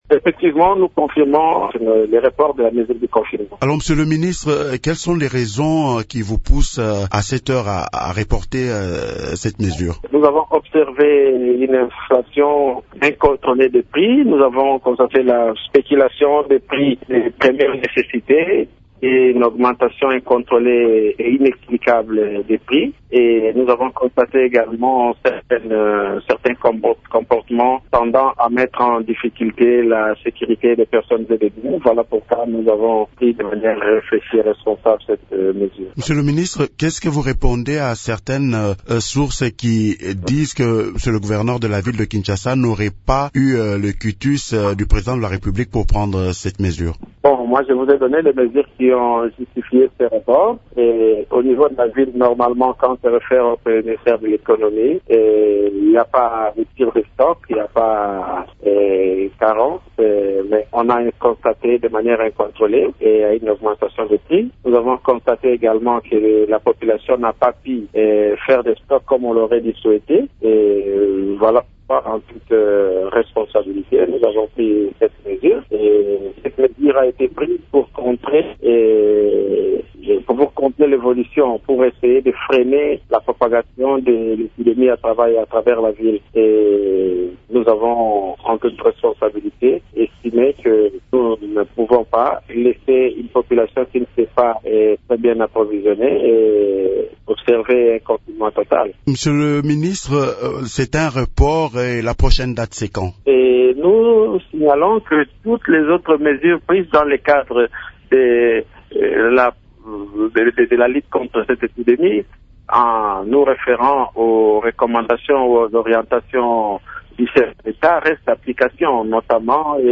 Vous pouvez écouter le ministre Mbutamuntu dans cet extrait :